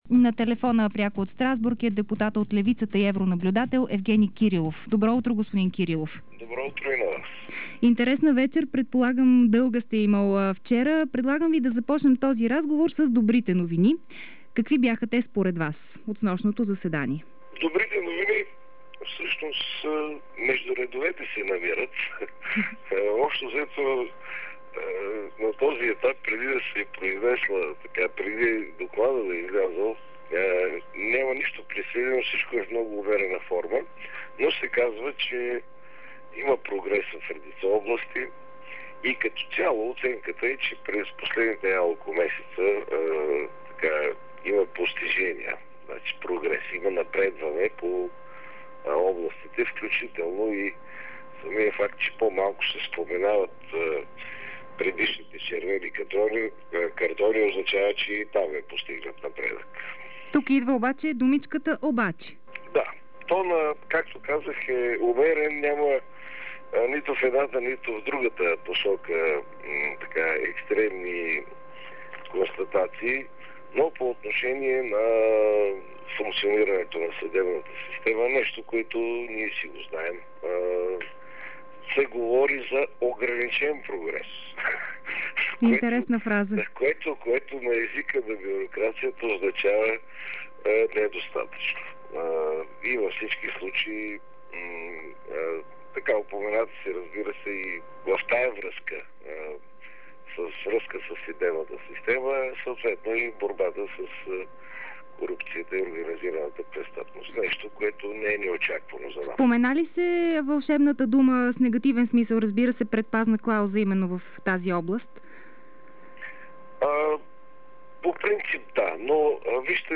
Евгени Кирилов, депутат от БСП и евронаблюдател за речта на еврокомисаря Оли Рен пред евродепутатите - интервю